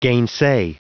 Prononciation du mot gainsay en anglais (fichier audio)
Prononciation du mot : gainsay